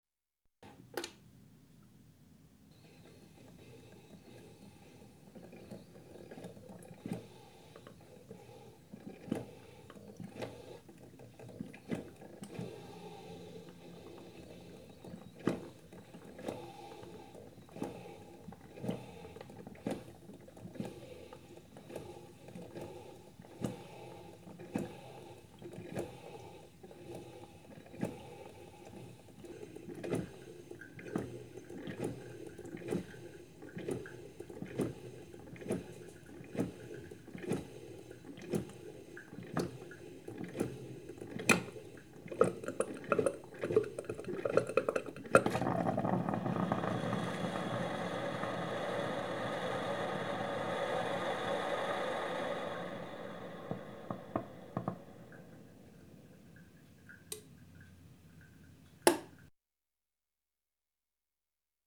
Kafeemaschinebrueht.mp3